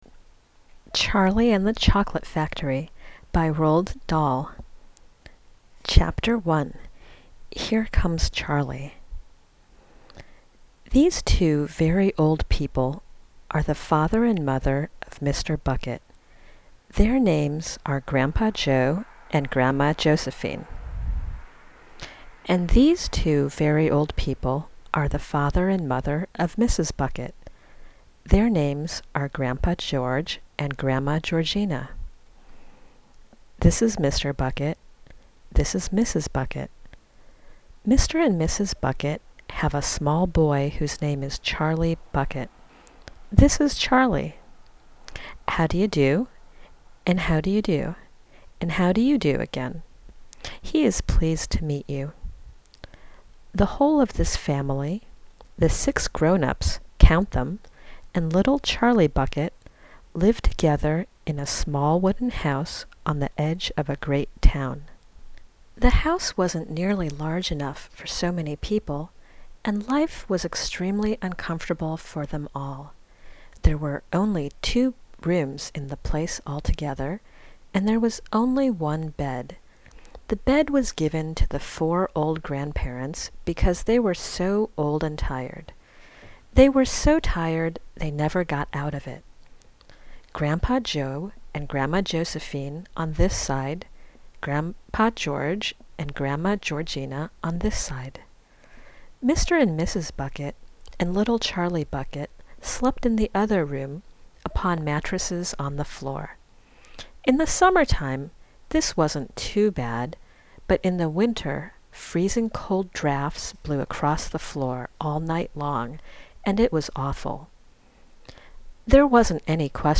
DIY Audio Book